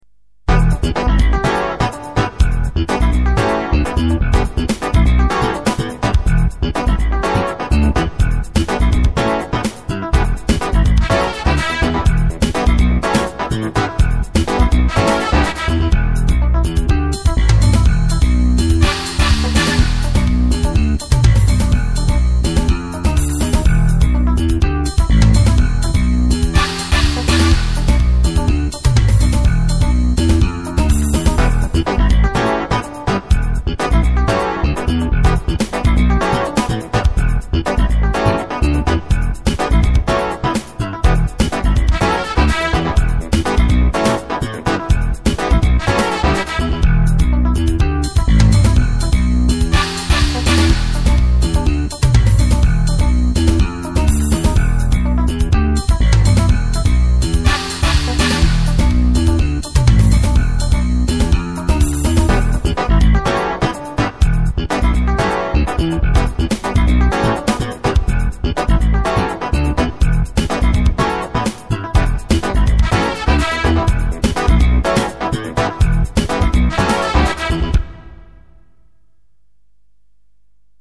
• Kurzweil Expander for grand piano, vibes and contra bass
• Rhodes 73 Keys piano
• Roland Expander 1040 for drums and strings
• Korg Wavestation
• Custom built  jazz guitar simulator with a Yamaha KX portable keyboard (vibrato by ribbon controller) an a Roland Sound Canvas; the two were connected over a single chip computer which I programmed in assembler; it did some kind of sound layering and a release sound.
Usually I would run the sequencer in a loop an do a live improvisation in parallel. Most of the pieces were instant compositions anyway. The result was recordet on a Sony DAT recorder.